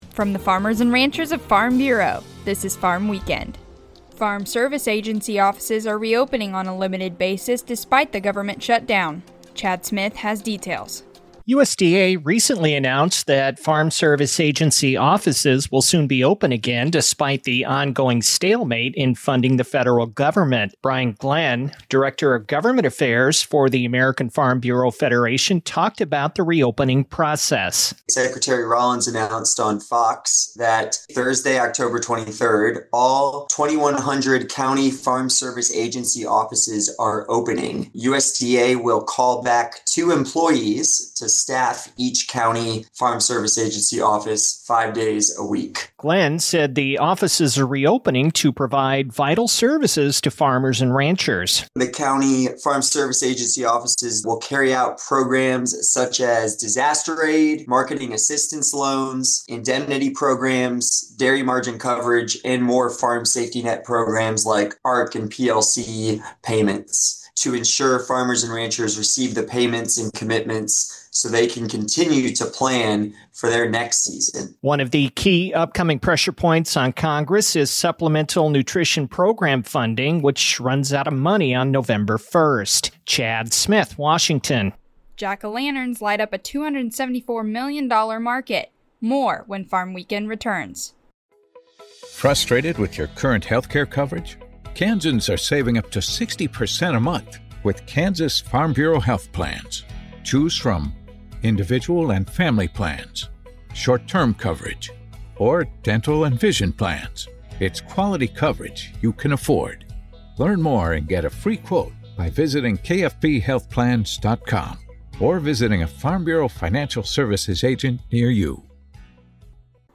A 5-minute radio program featuring a recap of the week's agriculture-related news and commentary.